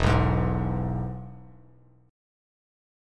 E Chord
Listen to E strummed